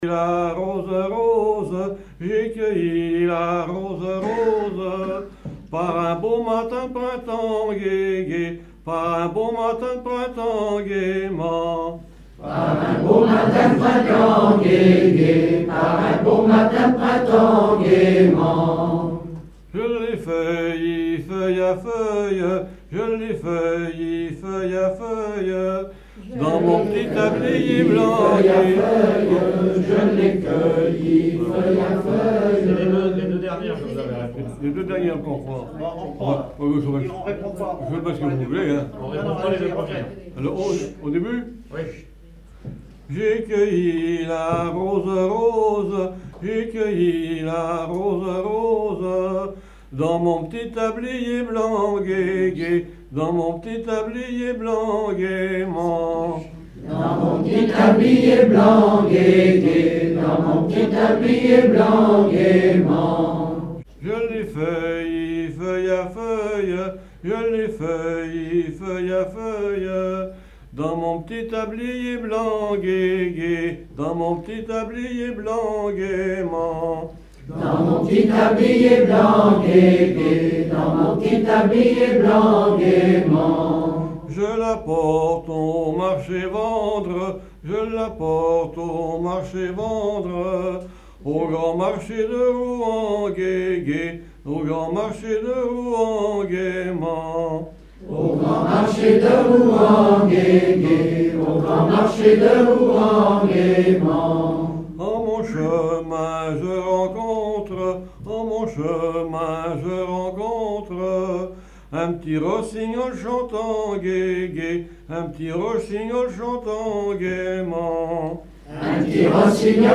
Genre laisse
Veillée (version Revox)
Pièce musicale inédite